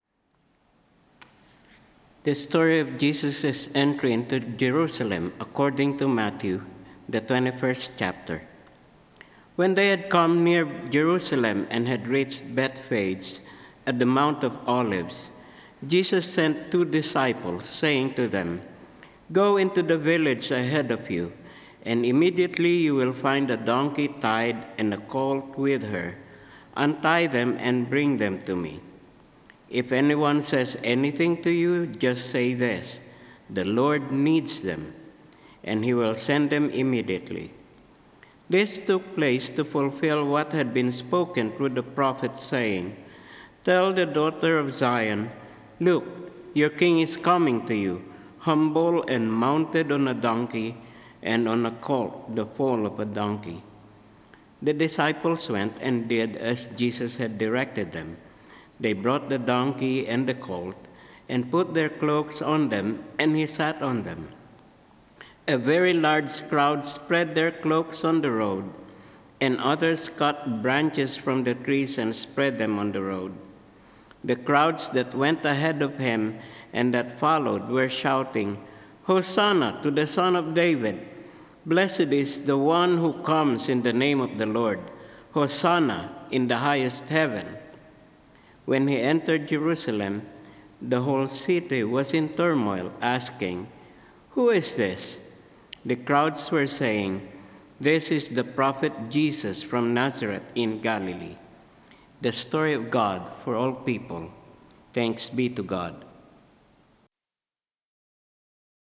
Service of Worship
Scripture Reading — Matthew 21:1-11 (NRSV)